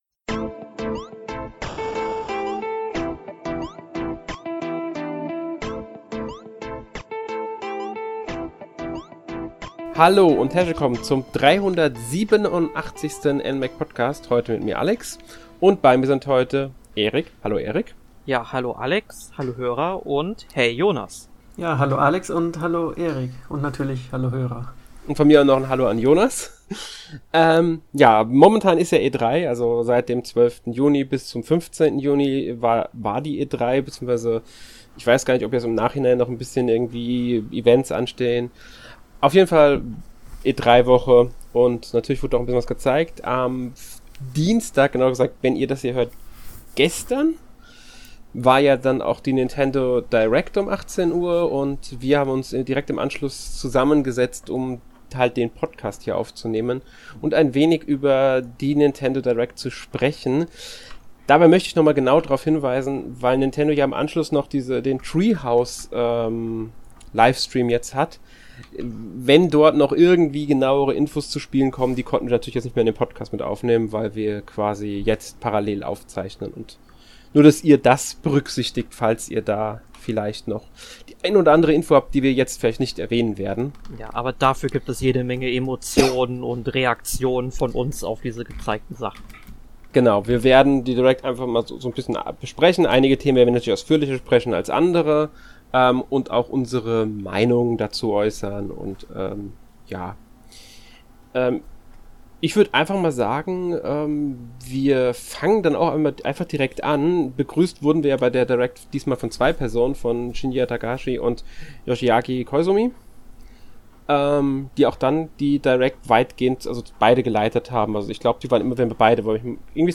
Außerdem ziehen die drei NMag-Redakteure ein Fazit zur Nintendo Direct und E3 2021 im gesamten.